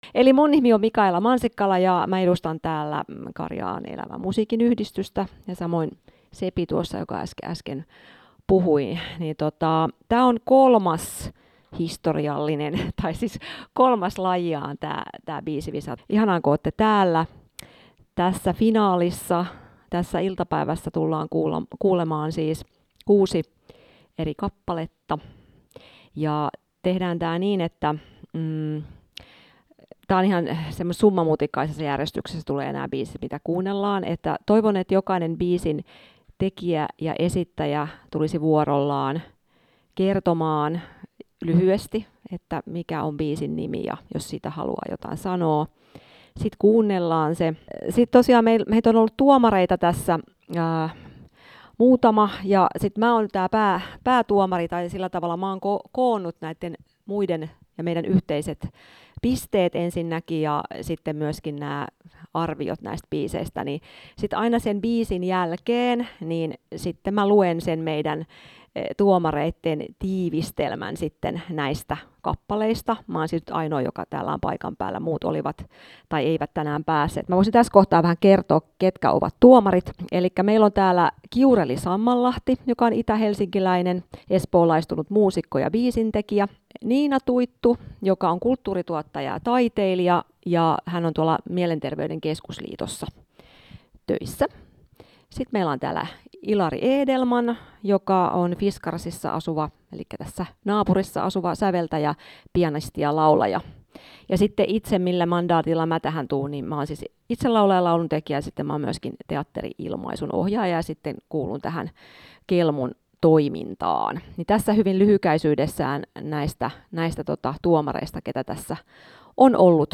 Kelmu ry (Karjaan elävän musiikin yhdistys) järjesti toukokuun alussa jo kolmannen kerran hyvän mielen kilpailun, Biisi–25.